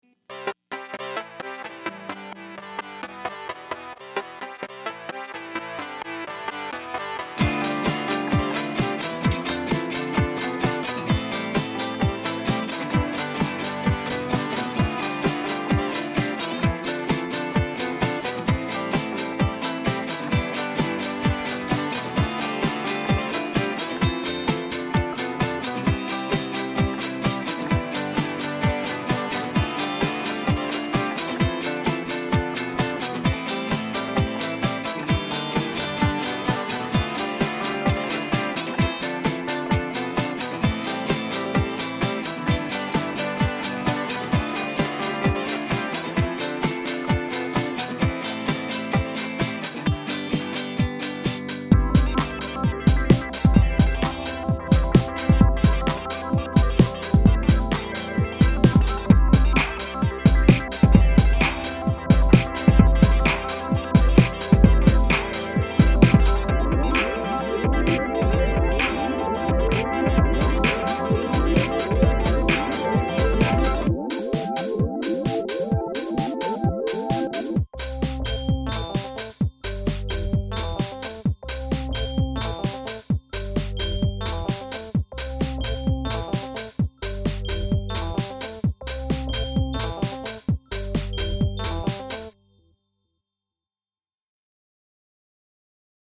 I was thinking for the chorus (sung to the flangey synth line, which is just the melody demo):